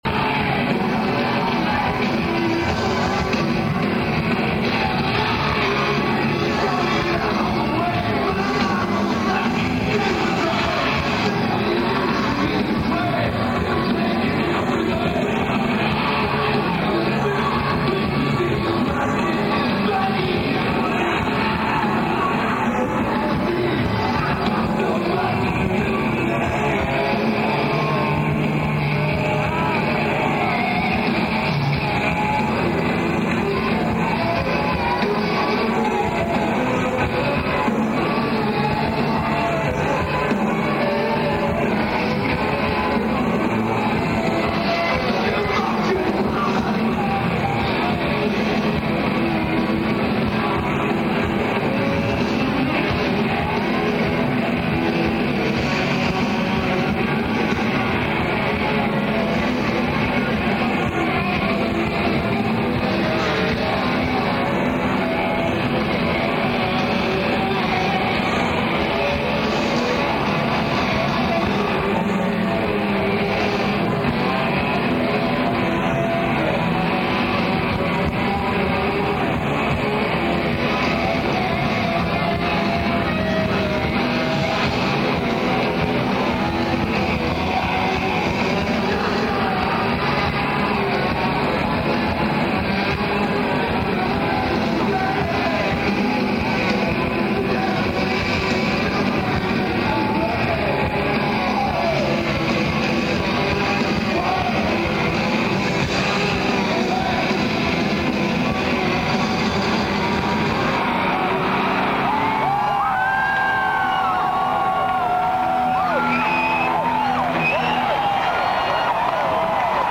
Molson Amphitheatre
Lineage: Audio - AUD (Internal Mics + Sony TCM-353V)